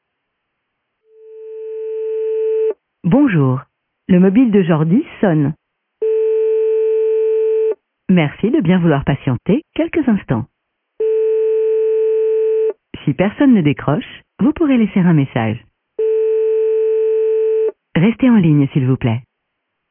Message Classique: Veuillez Patienter